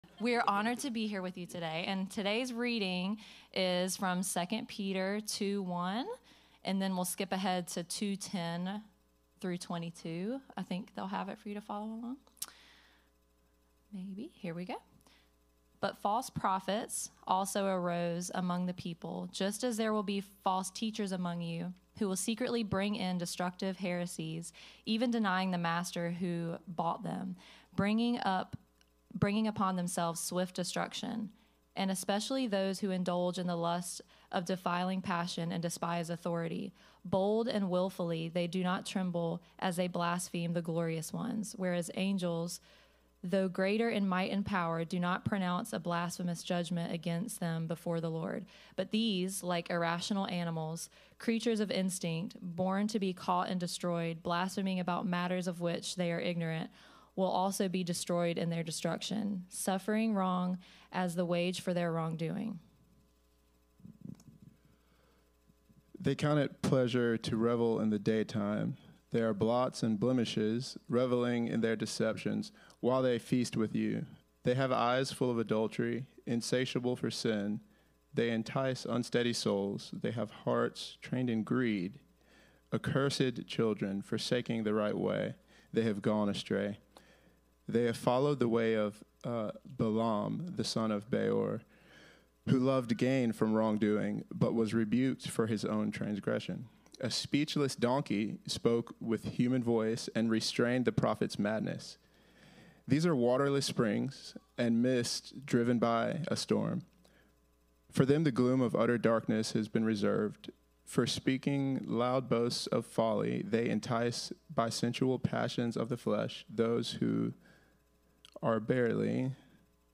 Series: Unshakable Service Type: Sunday 10am